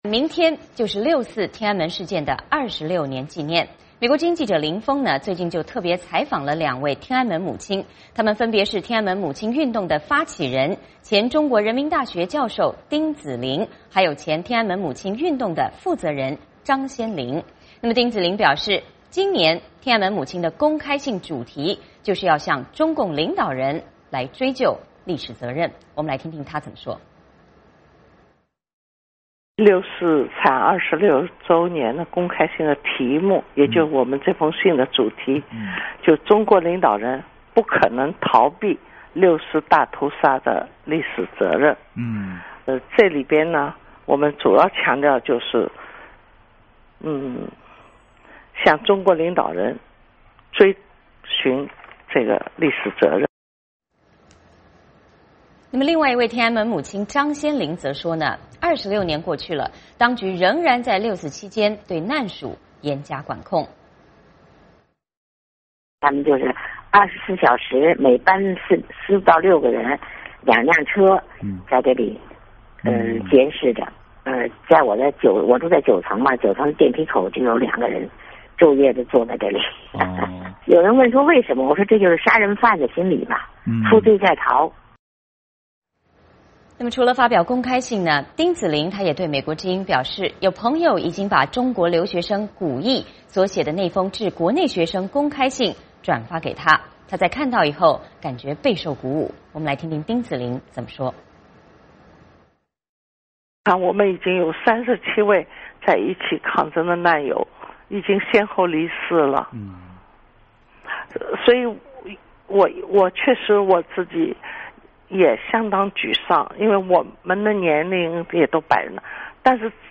六四前夕美国之音专访天安门母亲